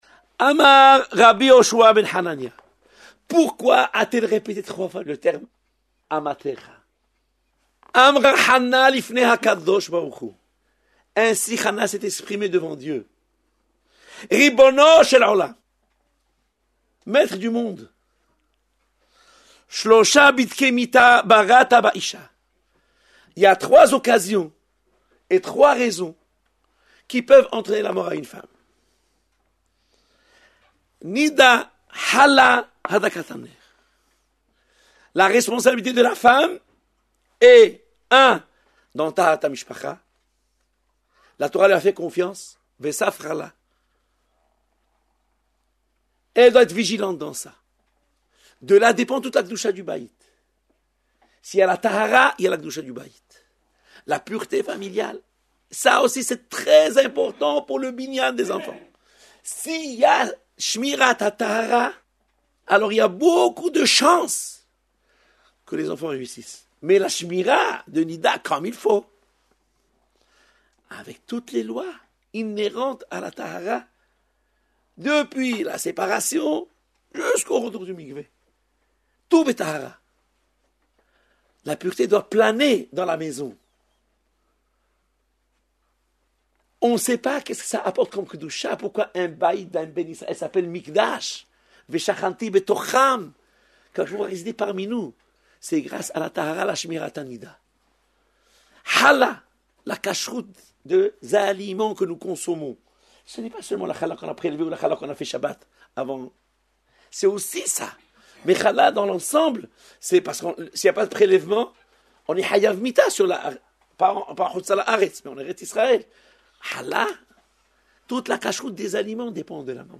Exposé magistral